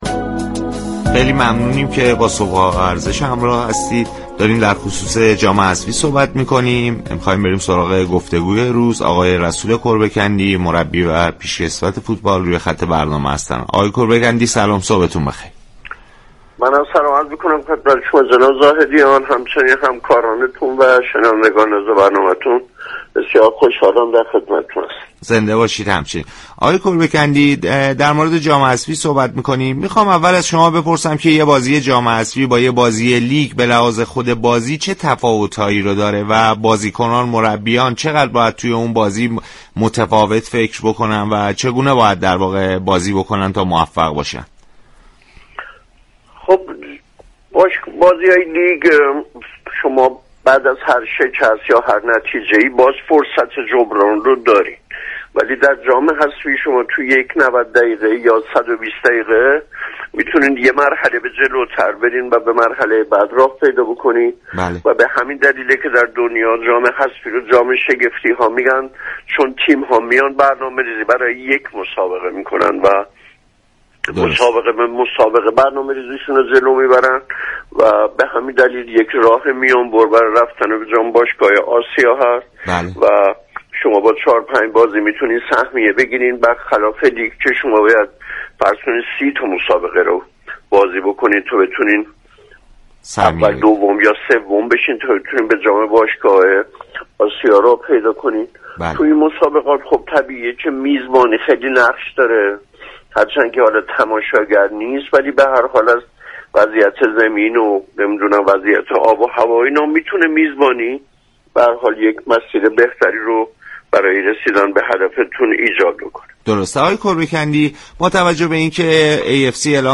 شما می توانید از طریق فایل صوتی پیوست بطور كامل شنونده این گفتگو باشید.